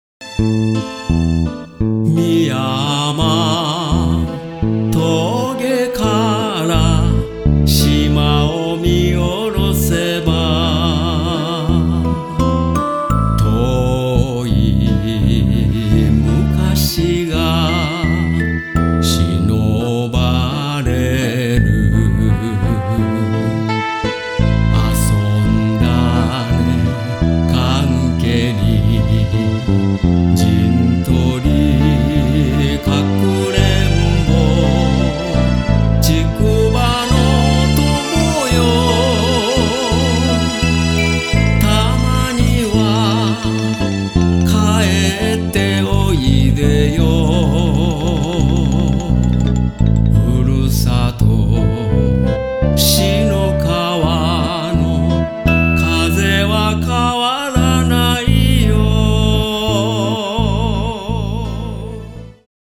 奄美歌謡